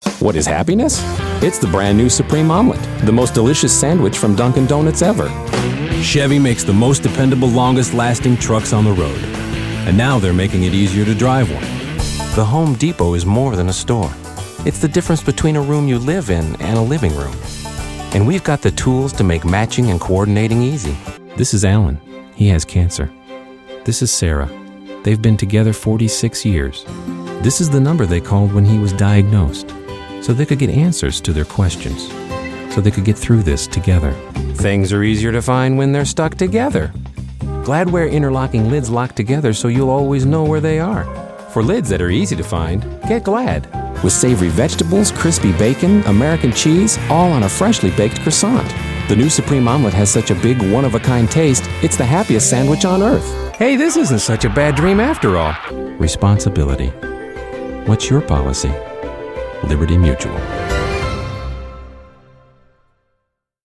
commercial : men